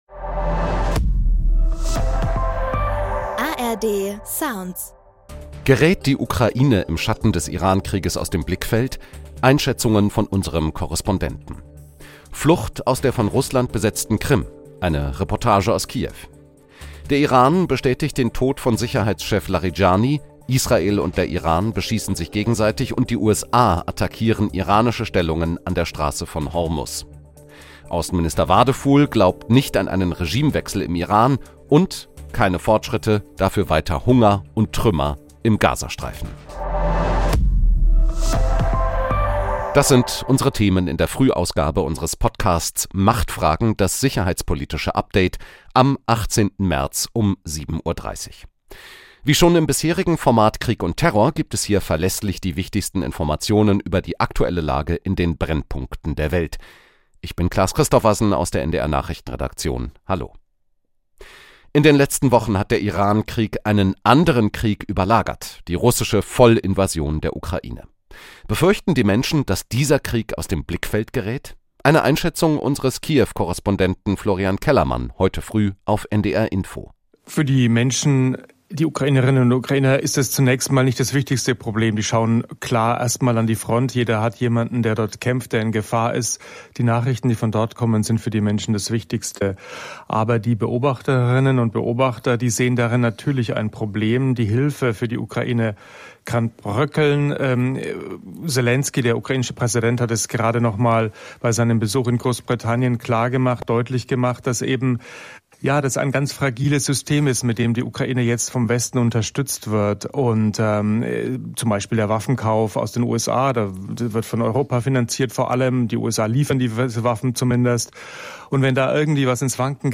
Einschätzungen von unserem Korrespondenten │ Flucht aus der von
Russland besetzten Krim - eine Reportage aus Kiew │ Der Iran